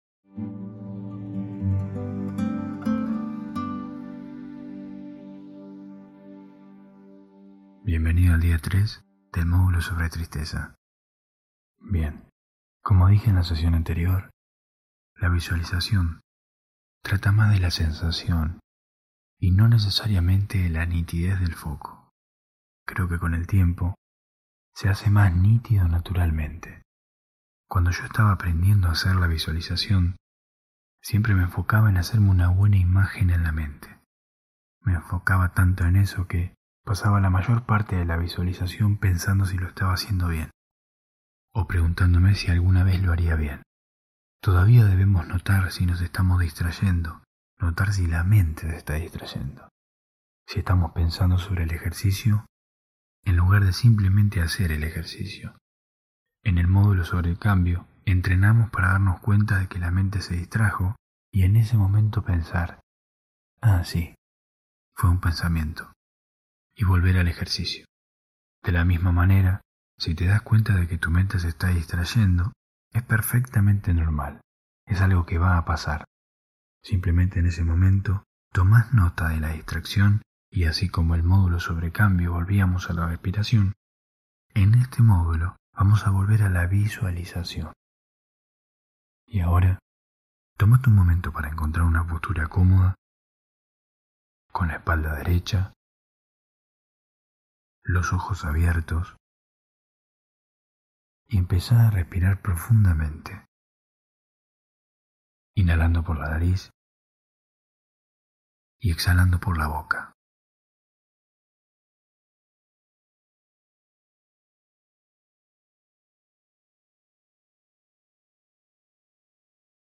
Cambiá la forma de relacionarte con la tristeza. Día 3 [Audio 8D. Mejor con auriculares] Hosted on Acast.